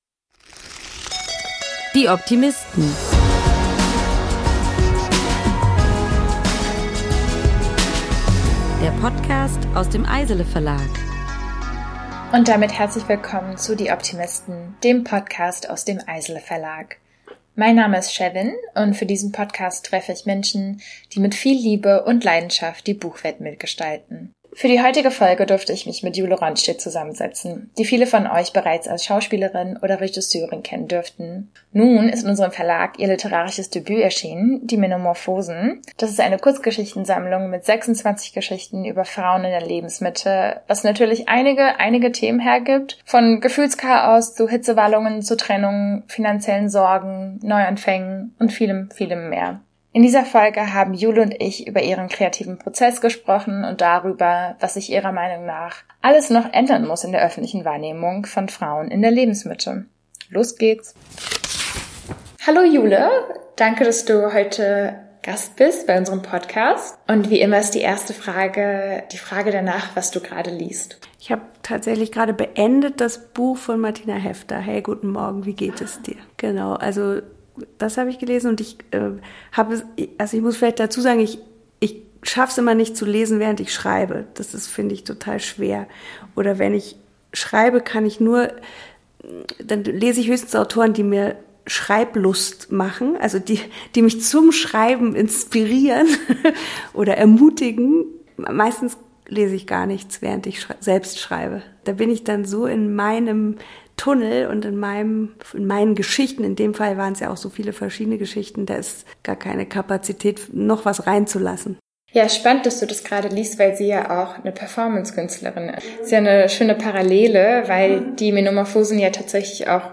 Wir haben mit Schauspielerin, Regisseurin und Autorin Jule Ronstedt über die »Menomorphosen«, ihr literarisches Debüt, gesprochen. In der heutigen Folge geht es um die Unterschiede von literarischen Texten zu Drehbüchern, um Frauen in der Lebensmitte, um neue Chancen und vieles mehr.